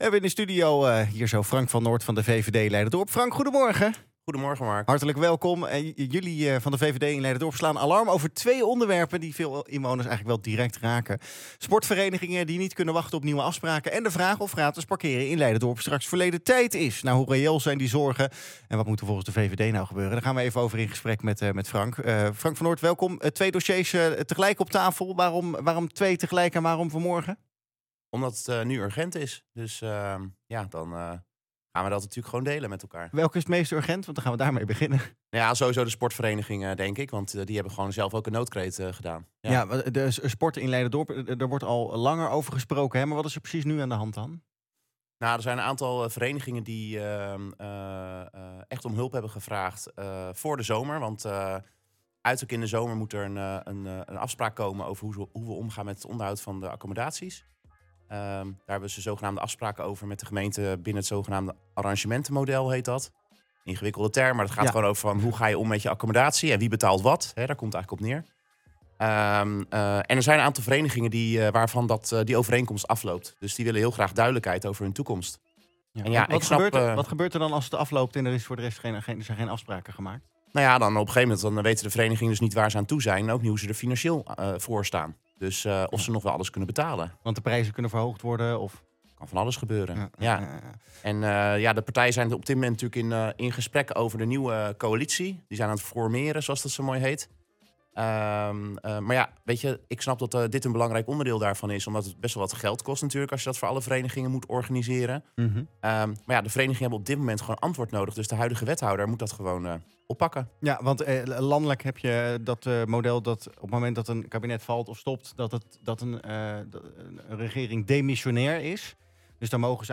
Fractievoorzitter Frank van Noort uitte in een interview zijn zorgen over de voortgang op beide onderwerpen en roept op tot snelle duidelijkheid vanuit het gemeentebestuur.
Interview Leiderdorp Politiek Sport